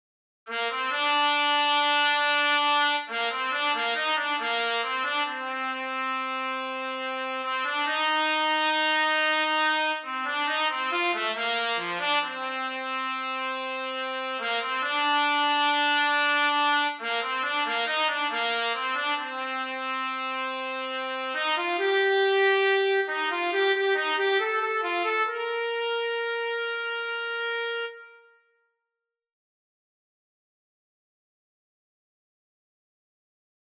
以下はAメロになります。お聞き頂ければ、もうお分り頂ける程美しく力強いメロディーですね。